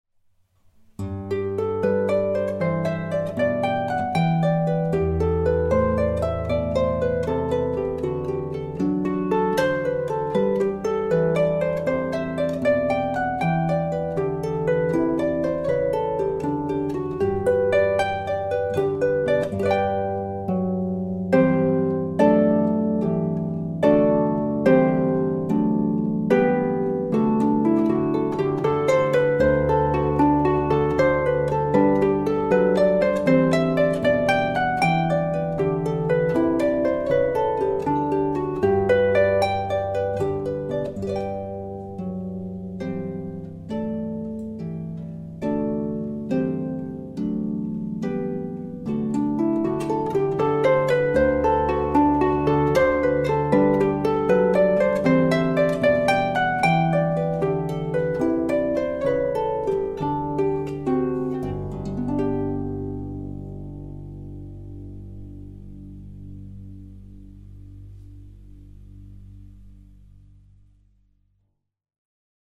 Toast Harpist